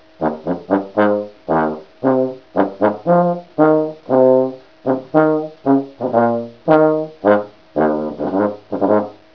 The Malta Band of Lancaster, PA.USA, is an adult amateur concert band in continuous operation since 1912, serving the Lancaster County and south-central area of Pennsylvania, USA.
tubaflug.wav